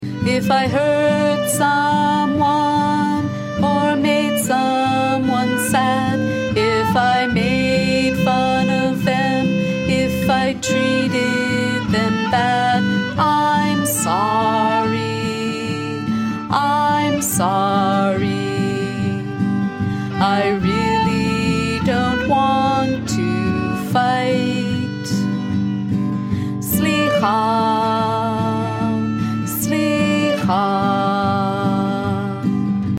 vocals and violin